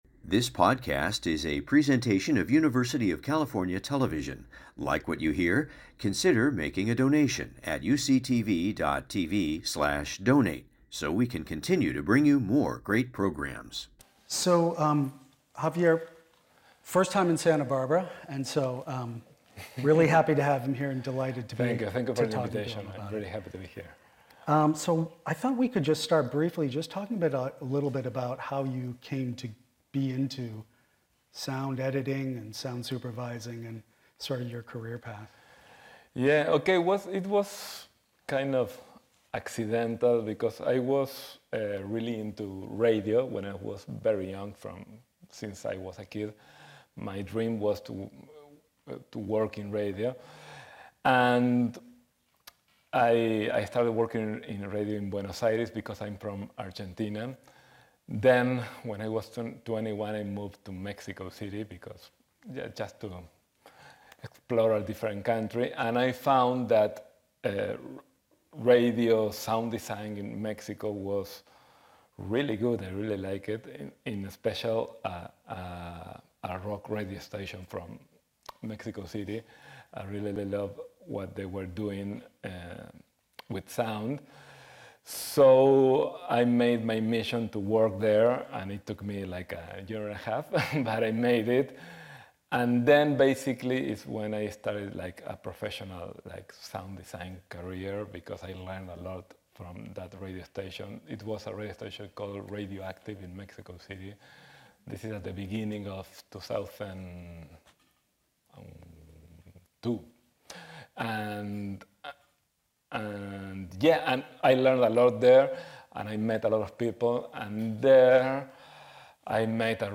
Go behind-the-scenes to learn more about the story-telling process as producers, directors, writers and actors discuss their craft.